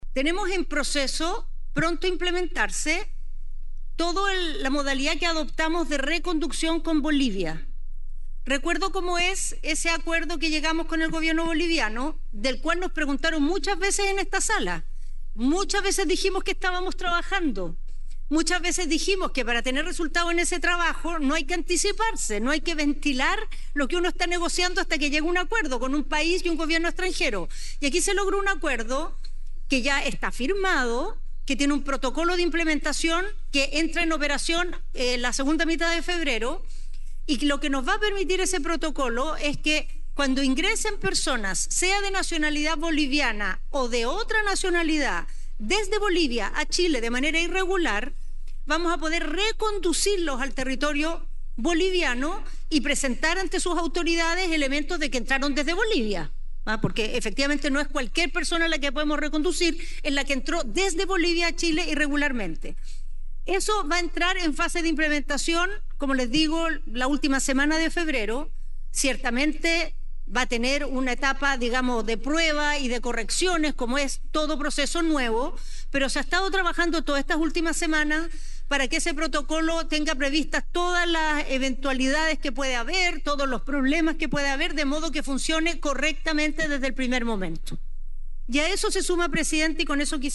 Discusión en Sala
No obstante, Tohá indicó que la baja en el número de detenidos es de un 7,7%, siendo menos pronunciada, porque hay una mayor capacidad para realizar detenciones, con la presencia de patrullas militares y procedimientos más efectivos que permiten actuar rápidamente.